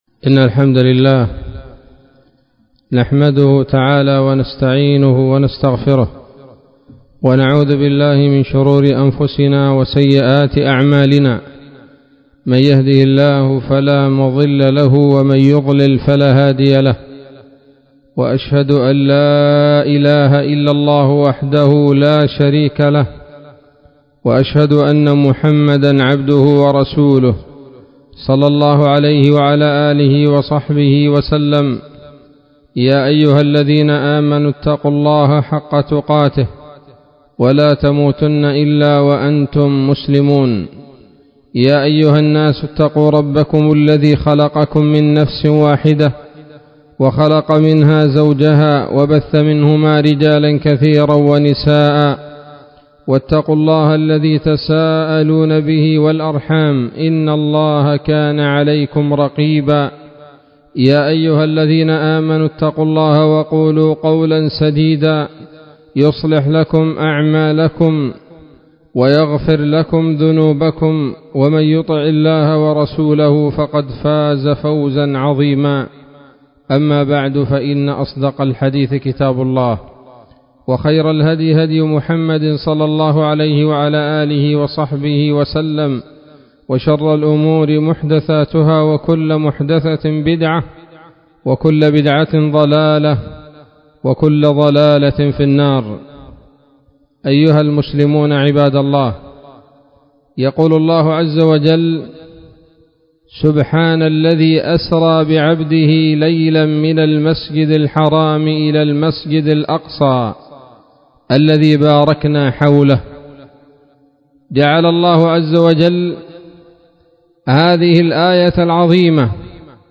خطبة جمعة بعنوان: (( كيف يُحرَّر الأقصا ؟